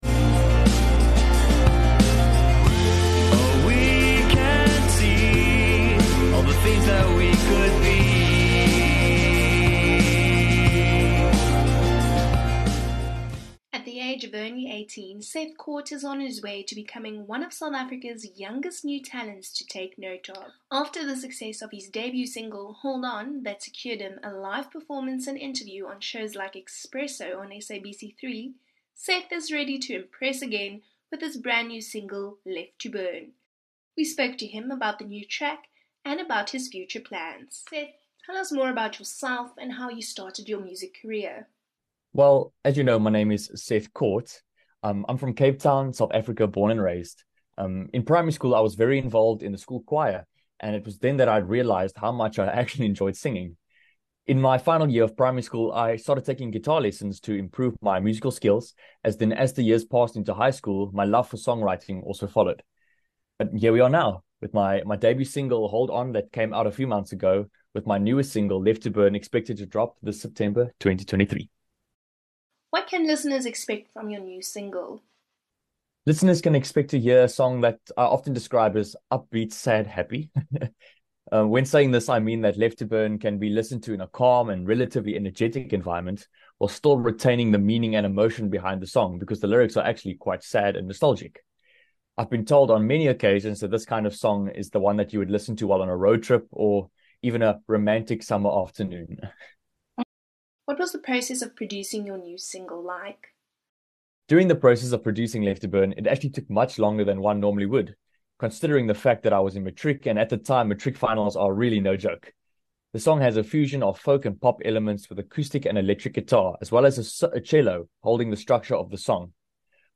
22 Oct INTERVIEW